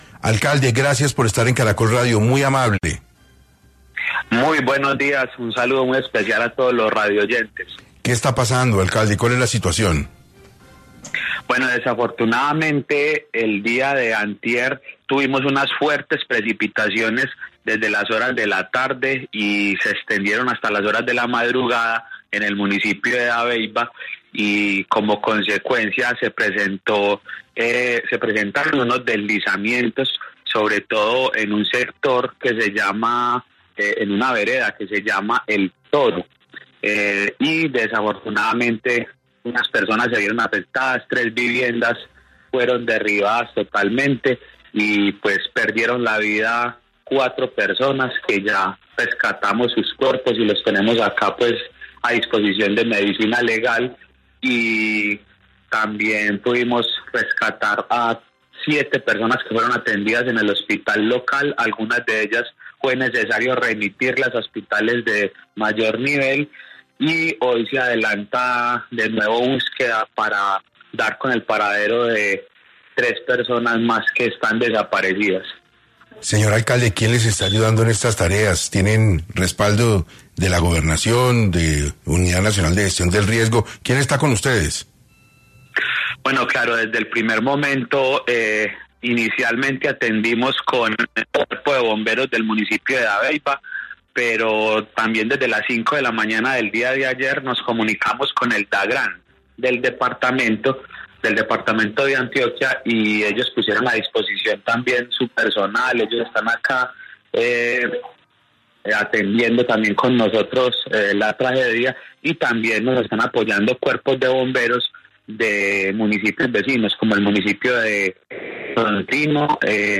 El alcalde de Dabeiba, Daniel Higuita, habló en 6AM de esta situación donde indicó que también pudieron rescatar a siete personas que fueron atendidas en el hospital local, algunas de ellas fue necesario remitirlas a hospitales de mayor nivel y hoy se adelantarán de nuevo búsquedas para dar con el paradero de tres personas más que están desaparecidas.